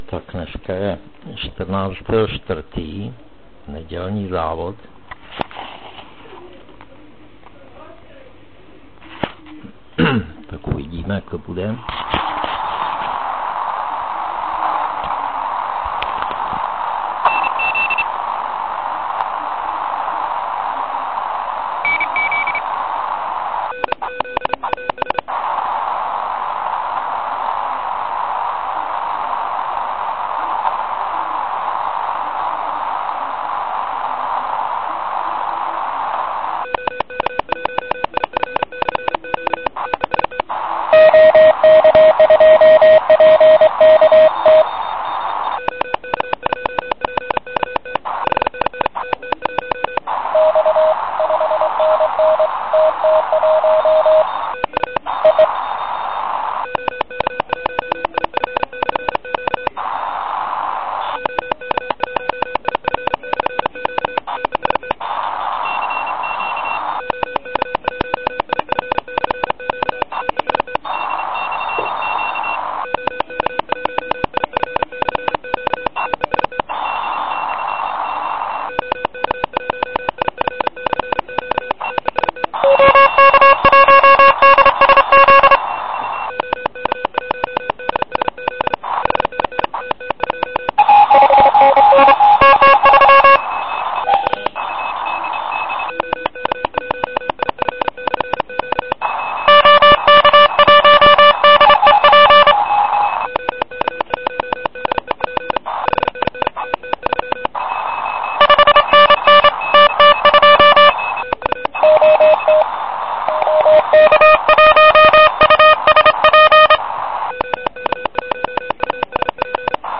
Zkr�tka to vše limit�, pr�sk�, vytv�ř� falešn� z�zněje, ale d� se to br�t. Tedy vynikaj�c� pomůcka pro v�uku z�voděn�. Ale poslechněte si třeba z�znam z neděln�ho z�vodu.